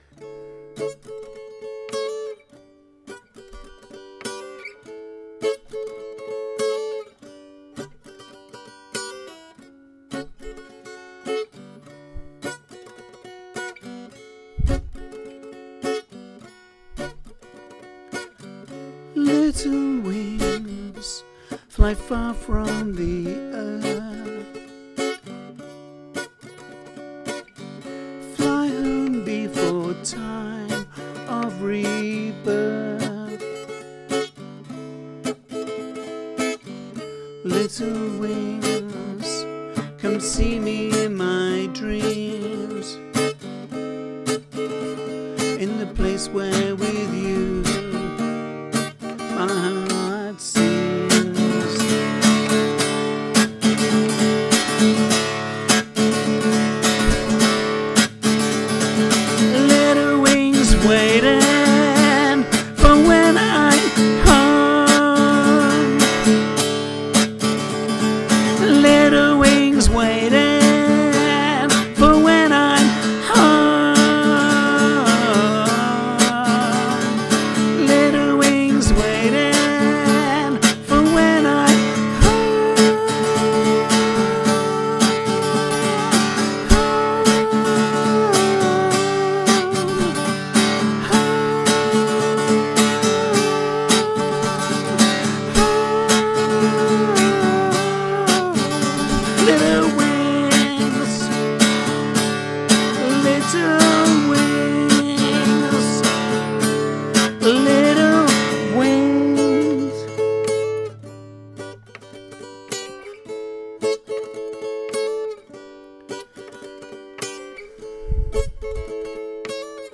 no rehearsal